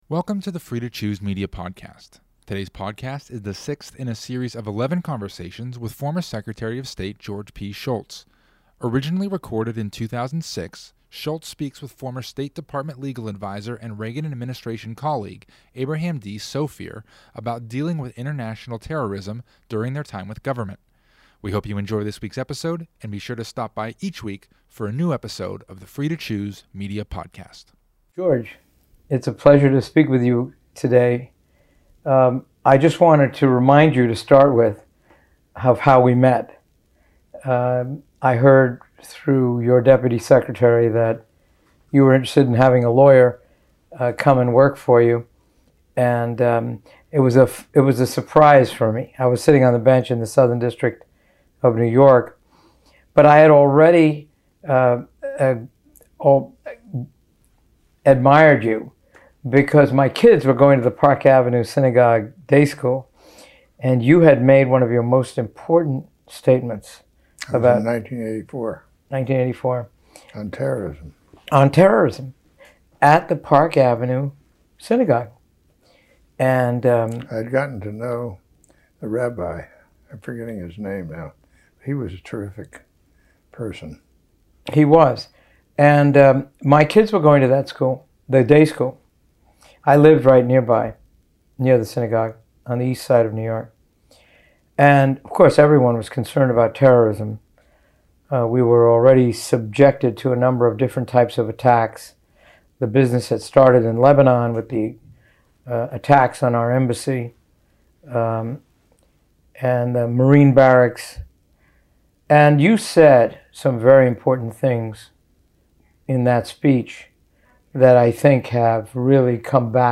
In this 2006 conversation, the sixth in a series of eleven, former Secretary of State George P. Shultz talks with former State Department legal advisor Abraham D. Sofaer. Having been colleagues in the Reagan administration, the two discussed their years together and talked about Shultz's 1984 speech on terrorism. They further investigated what it means to be prepared to use force in defense of the country, and used personal examples from the Reagan era to illustrate the concept of self-defense in preventing international terrorism.